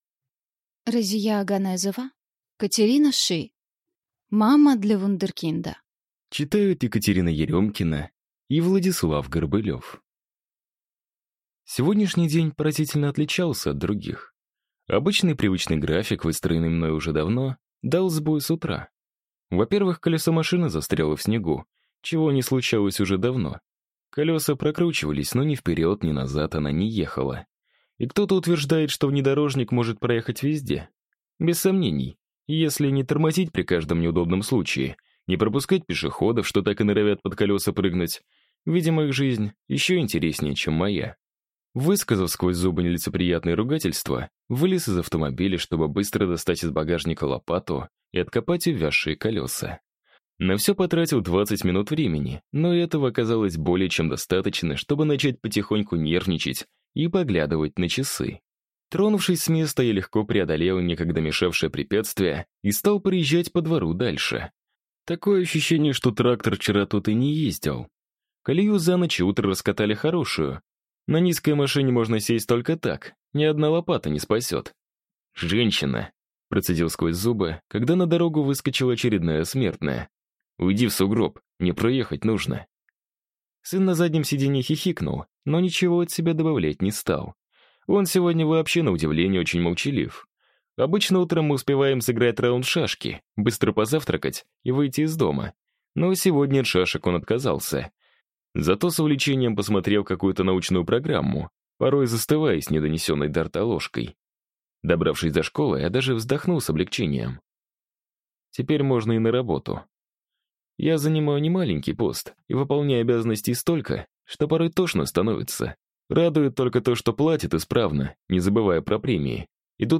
Аудиокнига Мама для вундеркинда | Библиотека аудиокниг